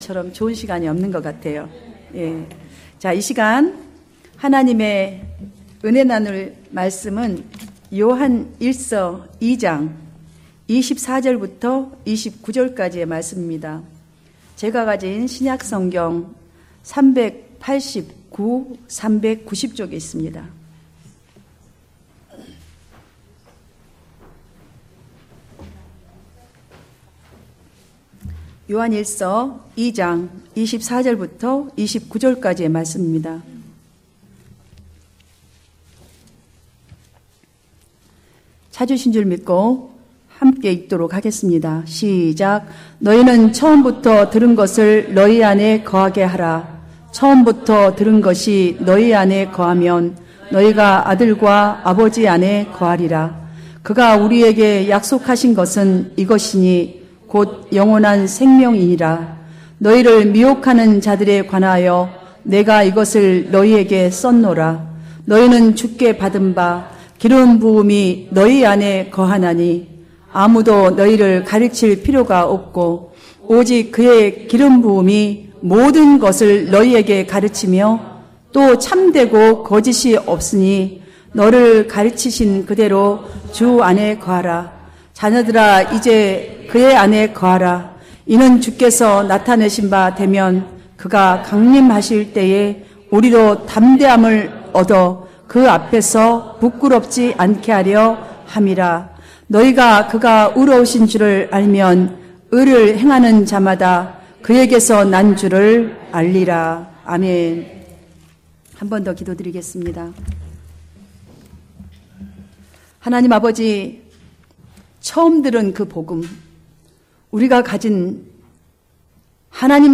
Sermons 2016